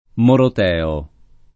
vai all'elenco alfabetico delle voci ingrandisci il carattere 100% rimpicciolisci il carattere stampa invia tramite posta elettronica codividi su Facebook moroteo [ morot $ o ] agg. e s. m. — della corrente della Democrazia Cristiana che faceva capo ad A. Moro (1916-78), distinguendosi dalla corrente dorotea